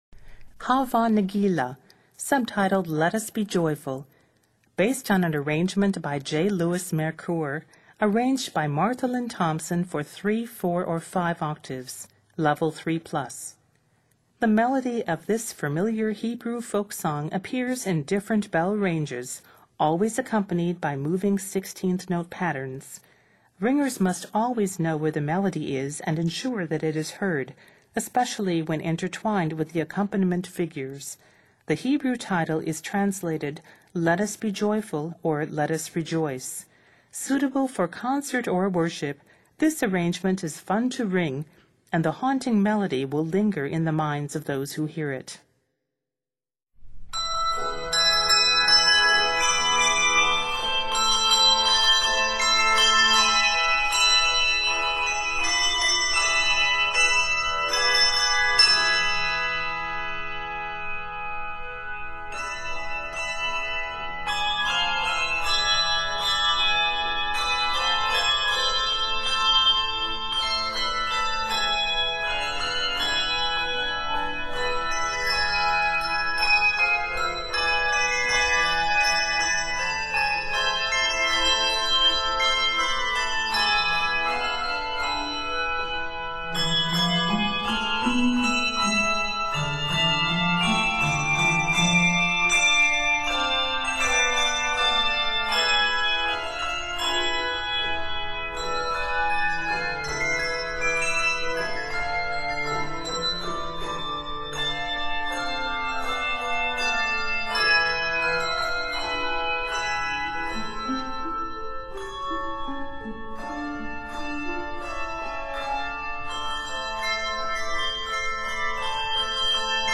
Set in a minor, measures total 64.